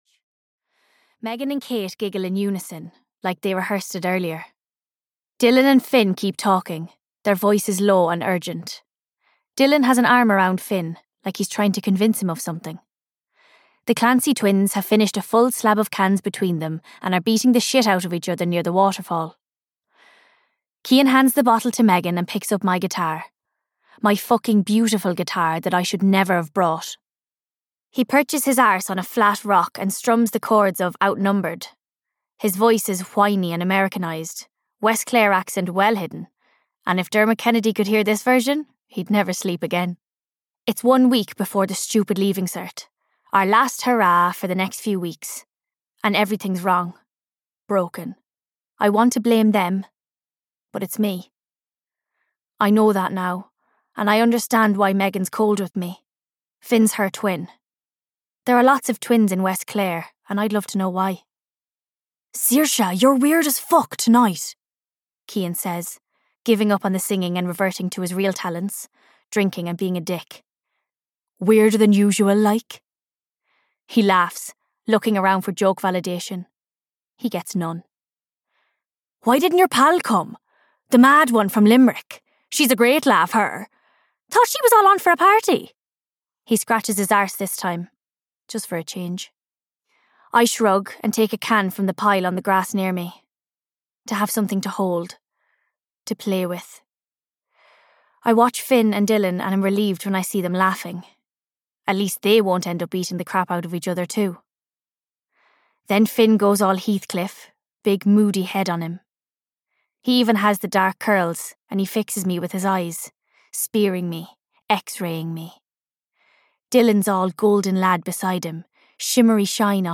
Things I Know (EN) audiokniha
Ukázka z knihy